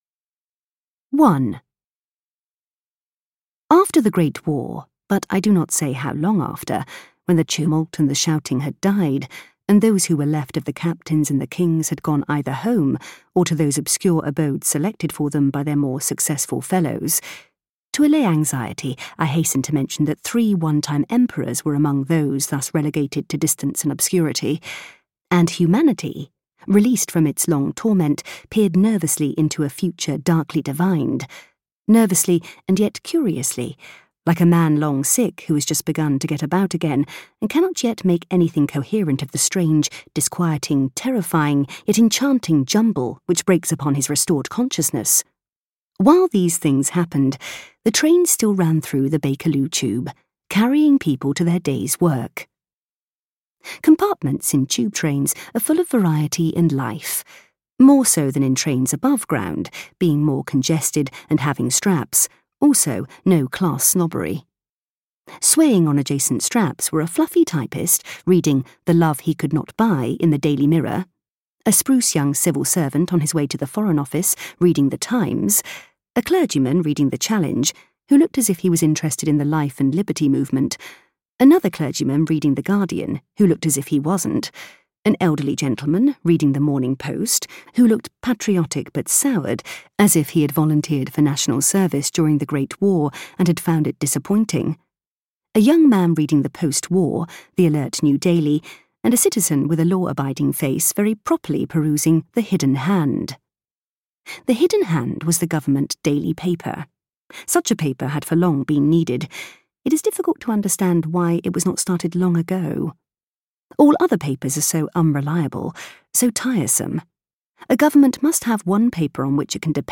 Audiobook What Not, written by Rose Macaulay. An early novel by Rose Macaulay about a government program of compulsory selective breeding in a dystopian future England.
Ukázka z knihy